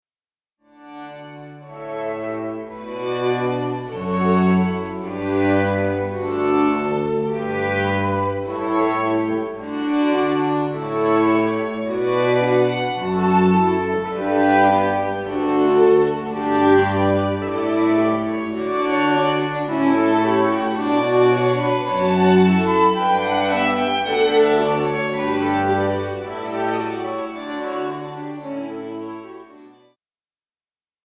Festliche Streicherklänge zur Zeremonie
A Streichquartett (unsere Grundbesetzung: 2 Violinen, 1 Viola, 1 Violoncello)
(Besetzung A: Streichquartett)